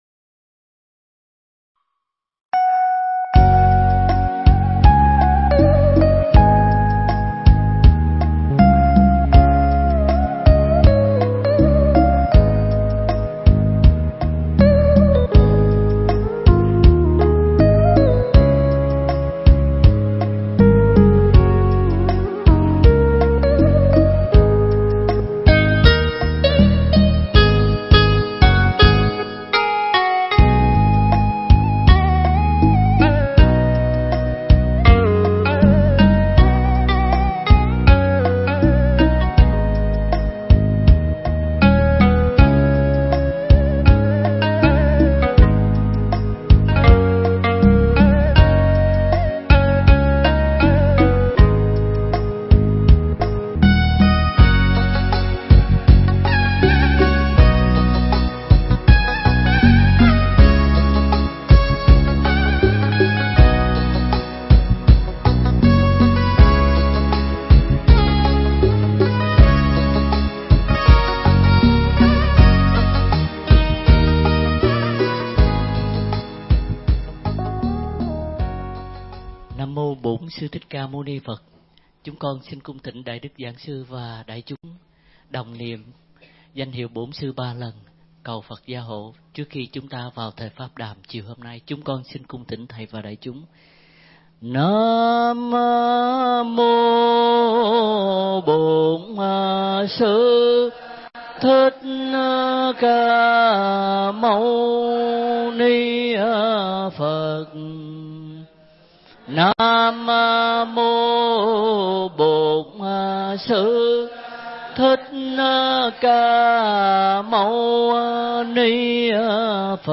Mp3 Pháp Thoại Phật Pháp Vấn Đáp Kỳ 35
giảng tại chùa Hoằng Pháp (Melbourne, Australia)